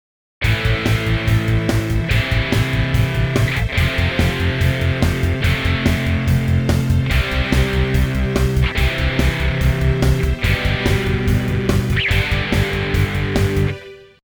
We will use the following chord progression: Am F C G.
On the next example, on the other hand, our aim is to use more than one chord shape to break the monotony of long-held chords.